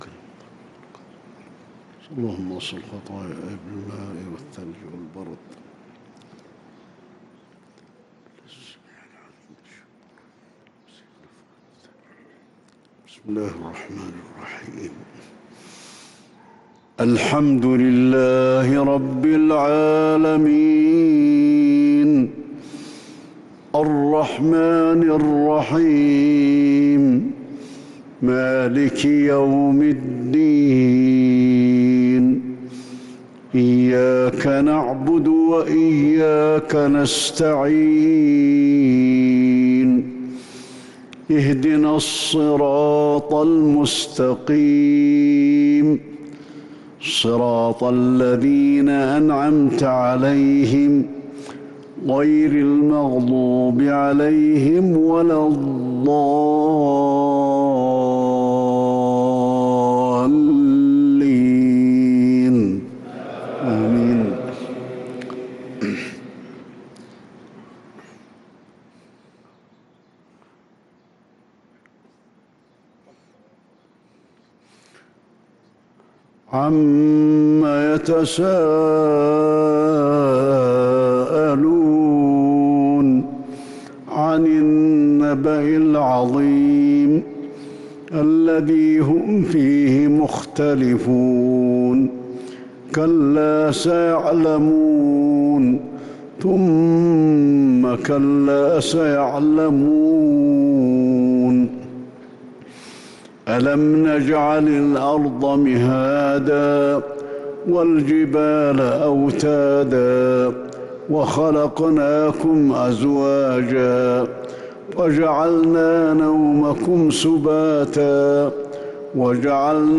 صلاة الفجر للقارئ علي الحذيفي 4 شوال 1443 هـ
تِلَاوَات الْحَرَمَيْن .